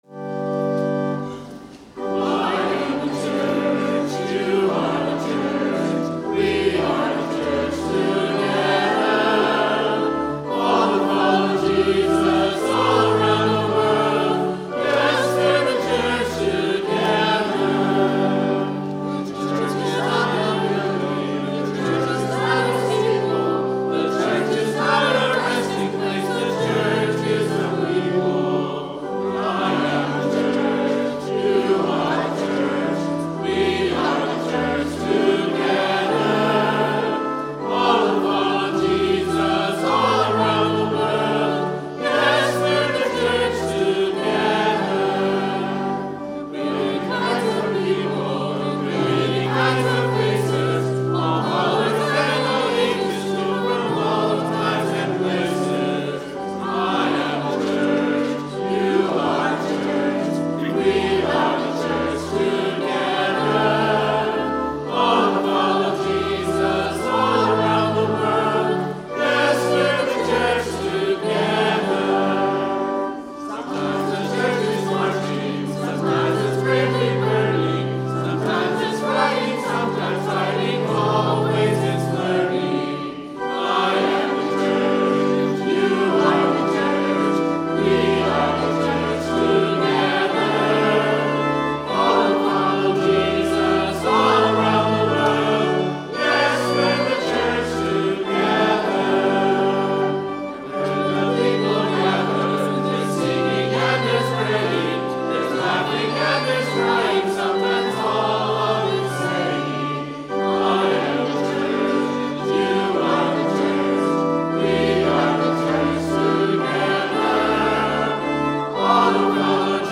Children’s Sabbath 2018 – 9:30